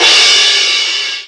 CRASH11   -L.wav